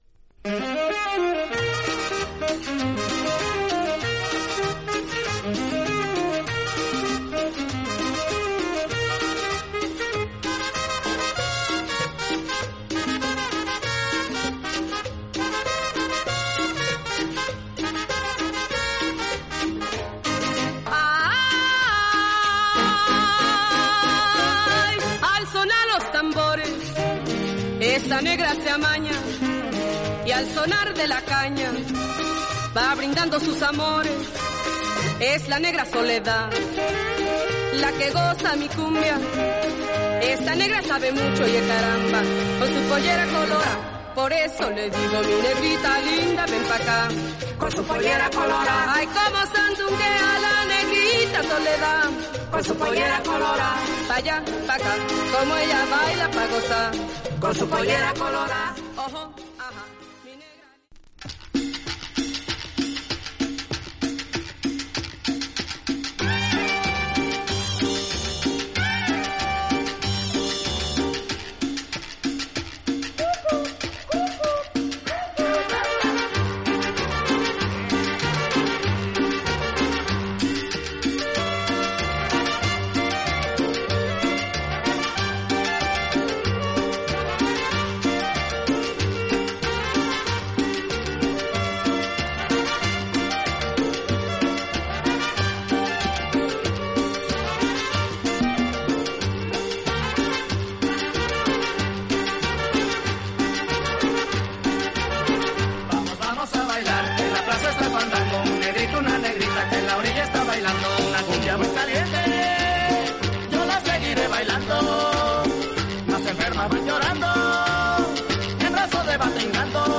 盤面薄いスレが僅かにありますが音に影響無く綺麗です。
実際のレコードからのサンプル↓ 試聴はこちら： サンプル≪mp3≫